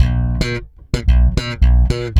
-AL DISCO.C.wav